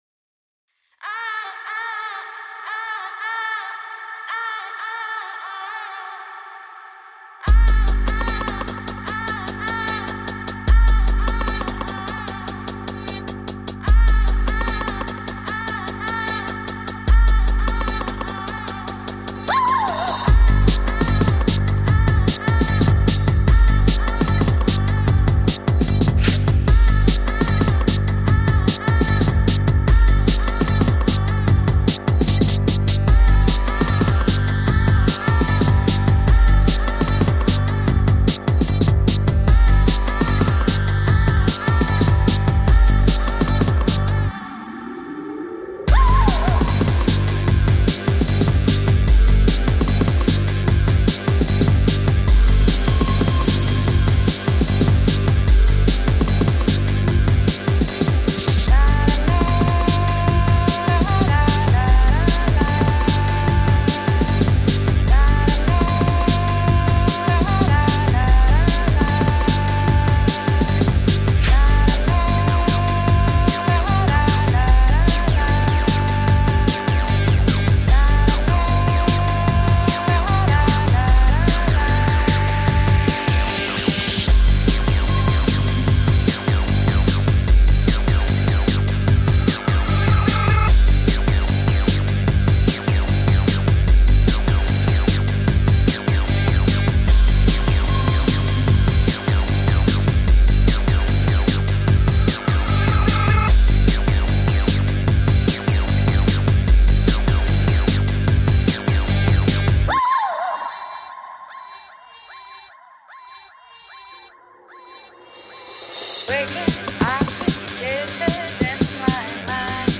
Tagged as: Electronica, Techno